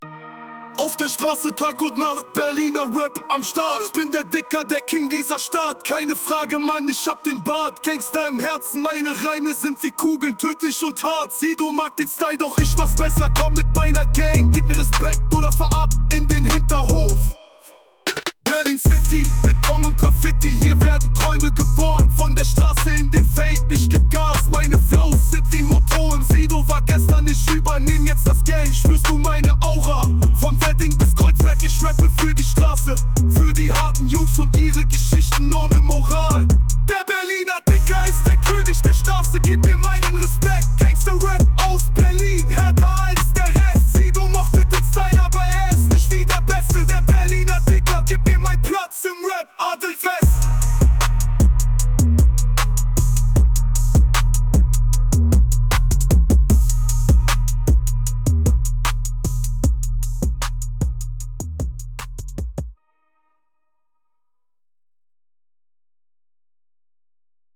AI Deutsch Rap
The greatest Hip Hop hits generated by AI.